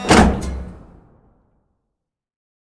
artillery-rotation-stop-1.ogg